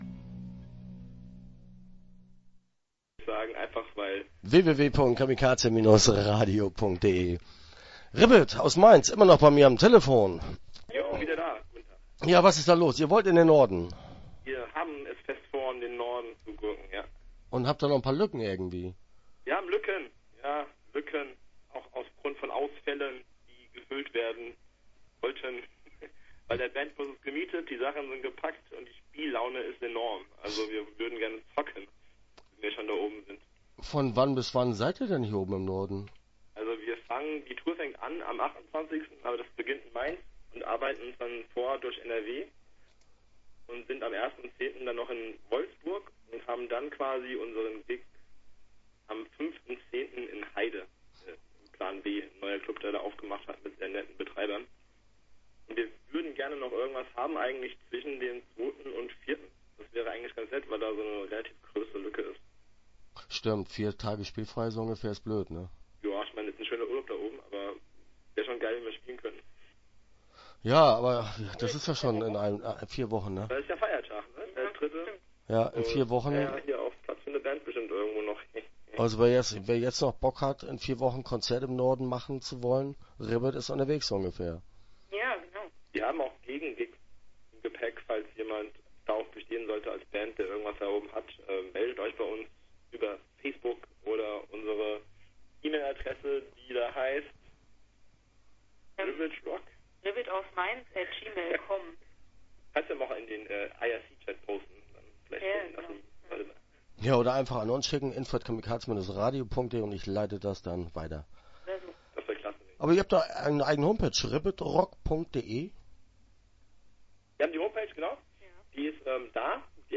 Start » Interviews » RIBBIT